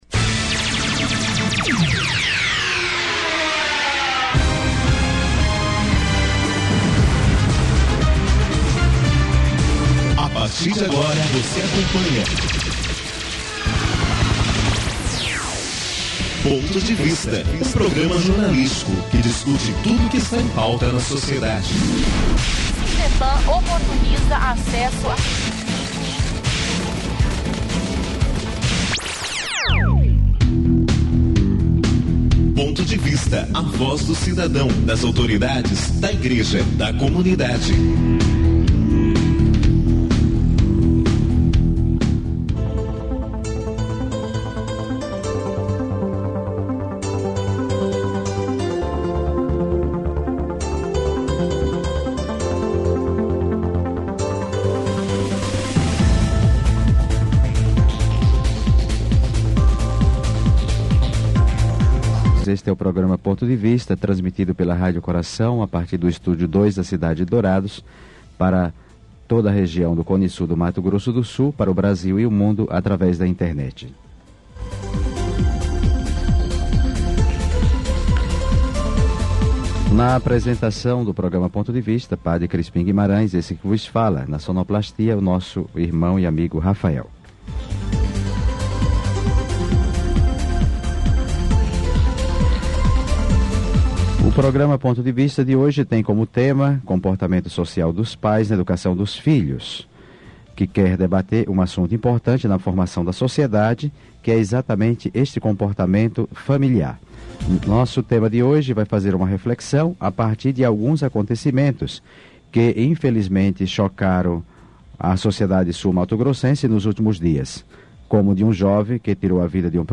Por telefone: